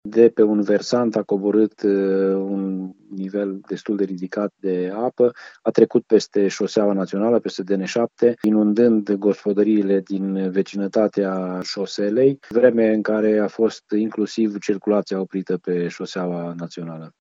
Probleme au fost și în centrul de comună, în Săvârșin, mai spune primarul Ioan Vodicean.